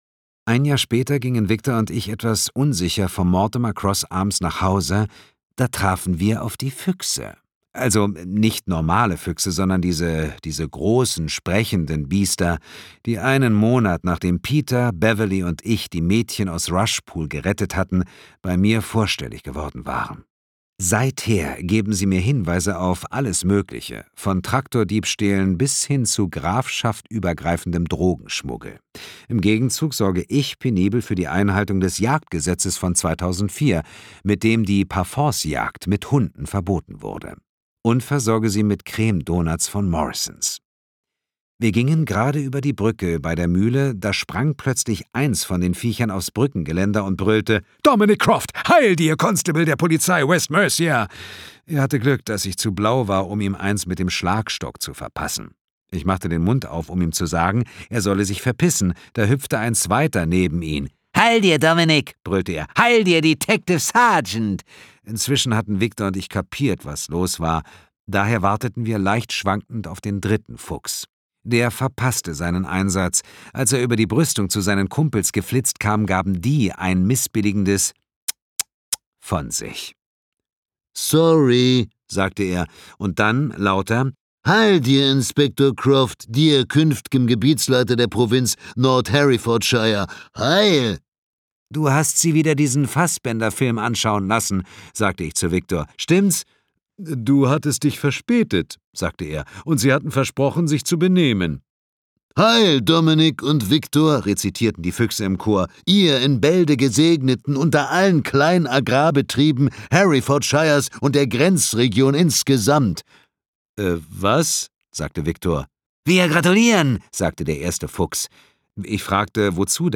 2021 | Ungekürzte Lesung
Mitarbeit Sprecher: Dietmar Wunder